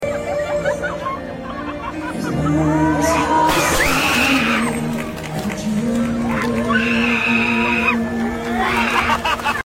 Orca sound effects free download